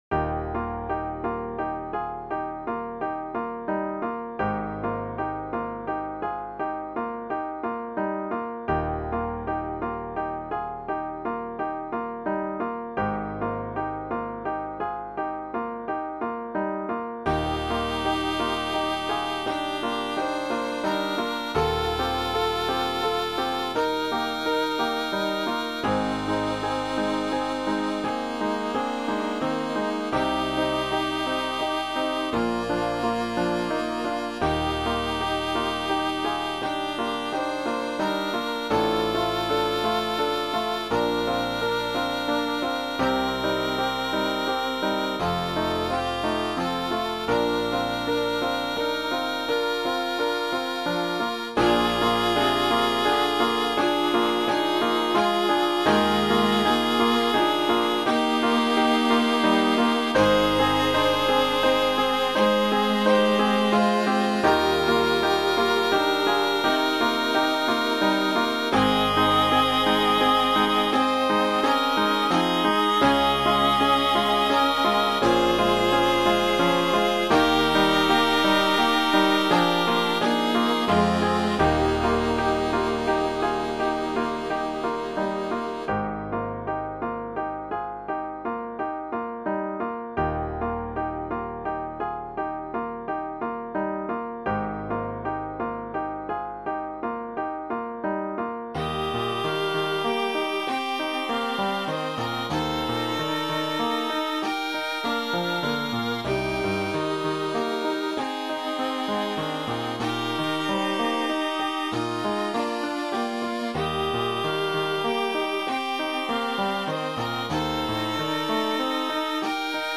This favorite hymn has been arranged as a string trio. It starts out slow and relaxed and speeds up a bit on verses 2 and 3. Each instrument gets to have the melody. The piano accompaniment is flowing and soothing.
Voicing/Instrumentation: Cello Duet/Cello Ensemble Member(s) , Violin Solo We also have other 34 arrangements of " More Holiness Give Me ".